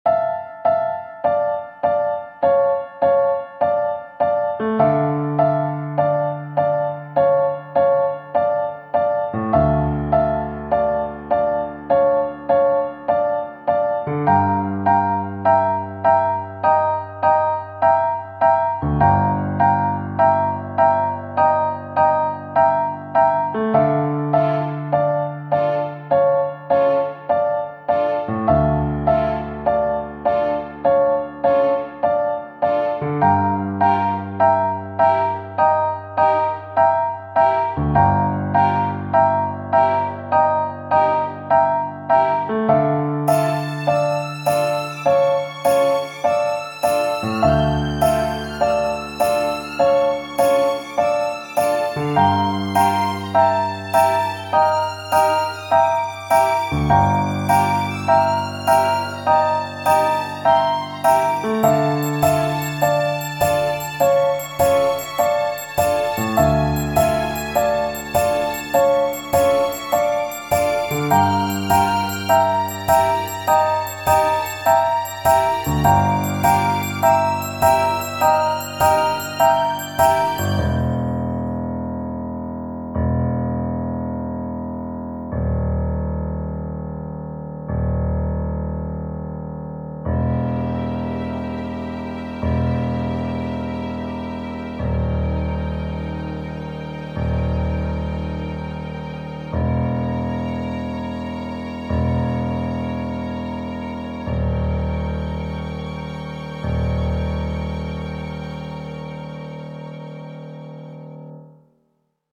ogg(R) 恐怖 静寂 ピアノ
重たいピアノ音色。